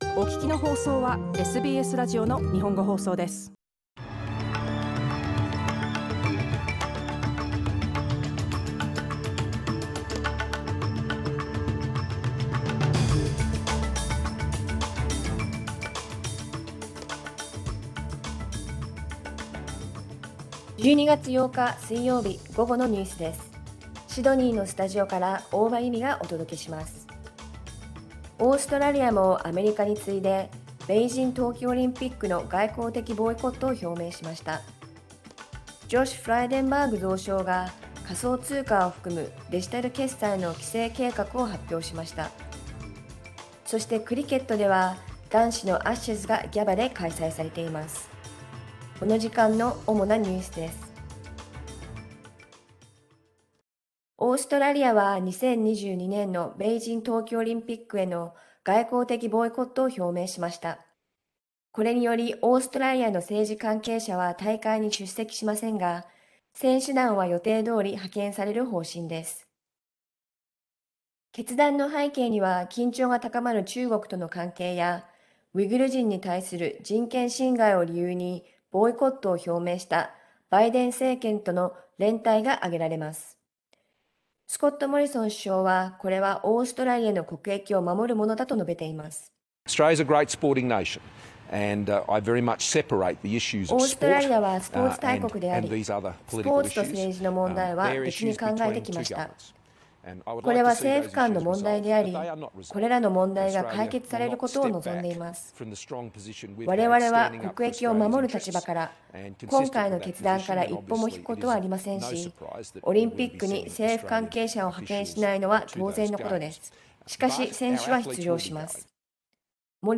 12月8日 午後のニュース
Afternoon news in Japanese, 8 December 2021